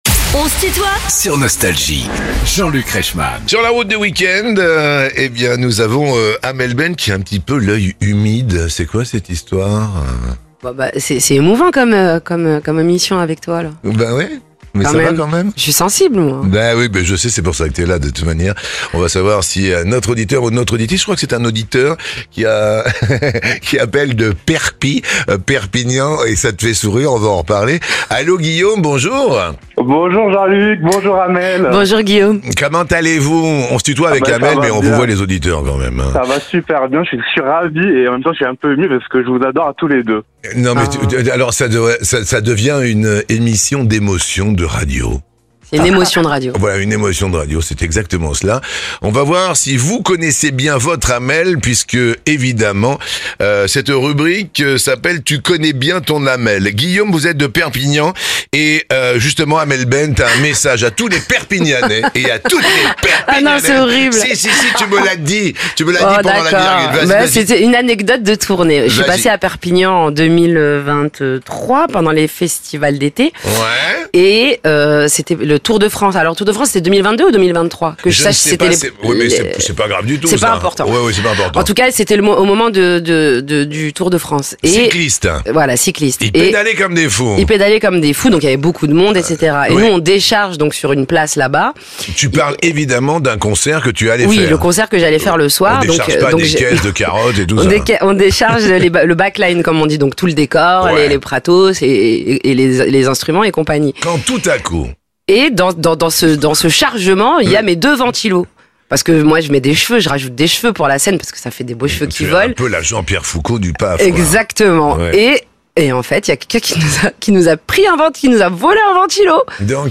Amel Bent invitée de On se tutoie ?..." avec Jean-Luc Reichmann